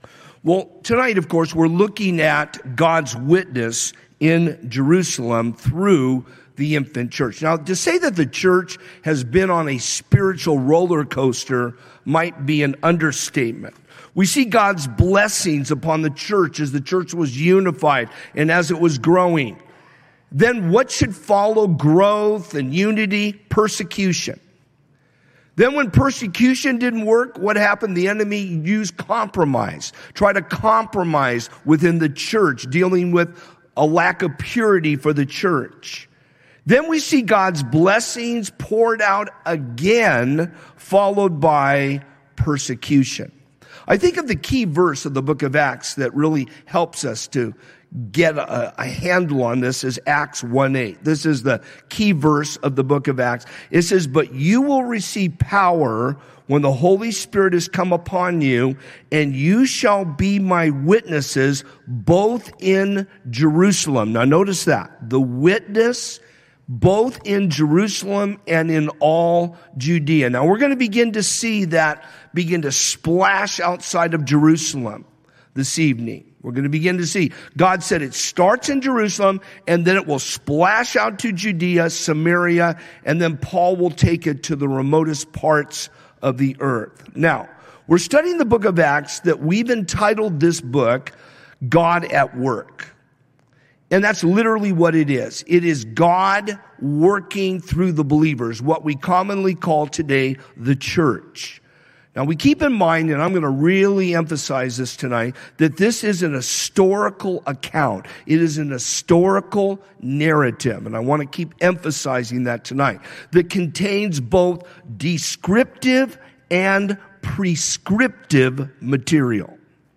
calvary_chapel_rialto_livestream-240p-online-audio-converter.com_.mp3